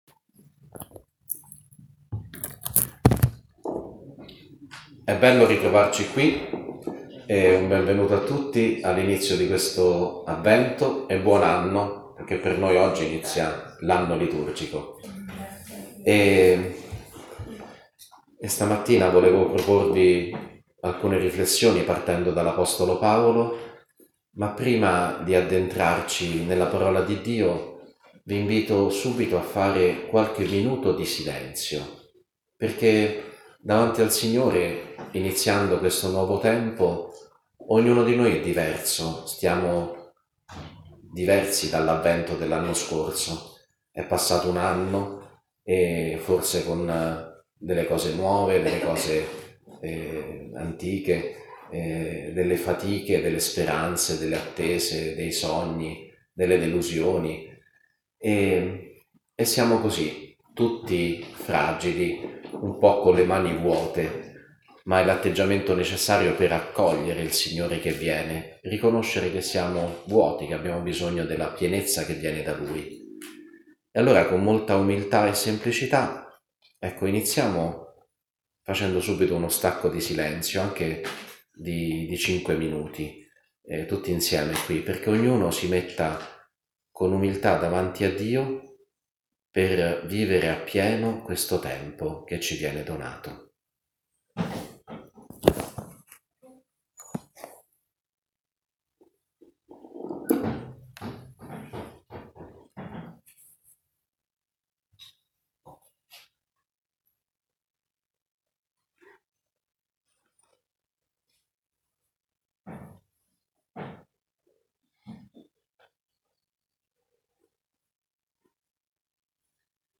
Giornata di Spiritualità Avvento 2019 – Riflessione di Mons. Paolo Ricciardi